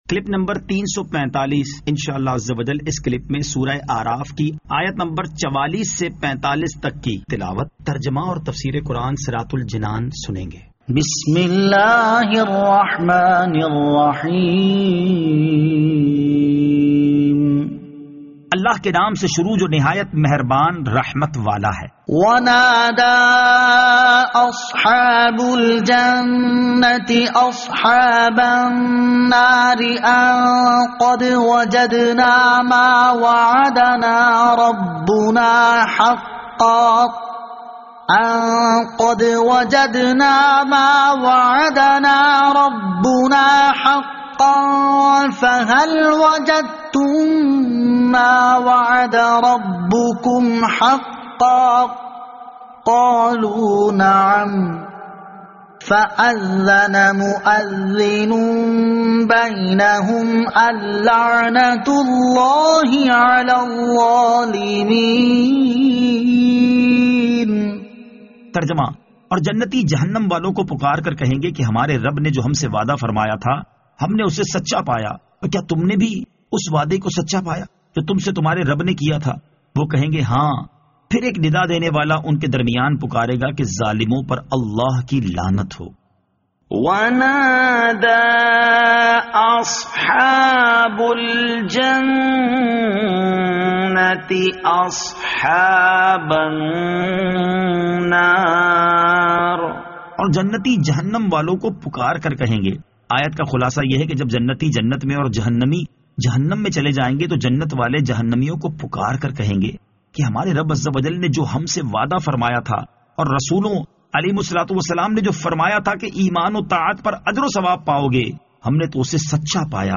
Surah Al-A'raf Ayat 44 To 45 Tilawat , Tarjama , Tafseer